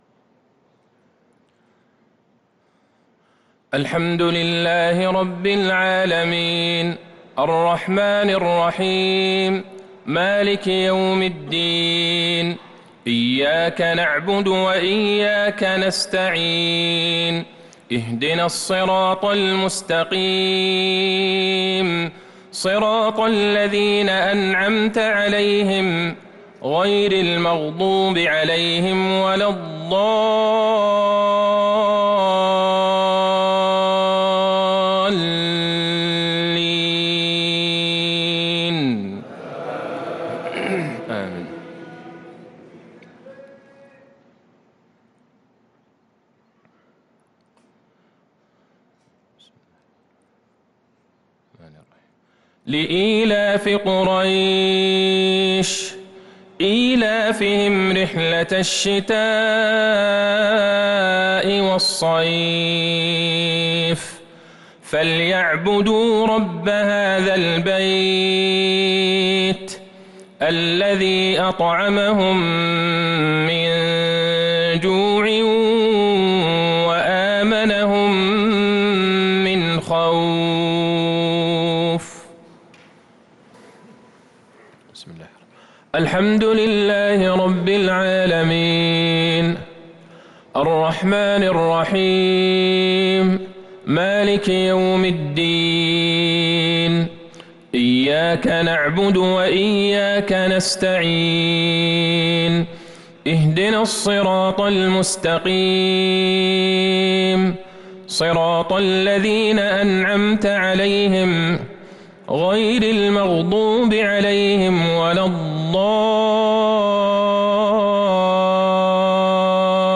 مغرب الثلاثاء ٤ رمضان ١٤٤٣هـ | سورتي قريش و الماعون | Maghrib prayer from Surah Quraish & Al-Ma’oon 5-4-2022 > 1443 🕌 > الفروض - تلاوات الحرمين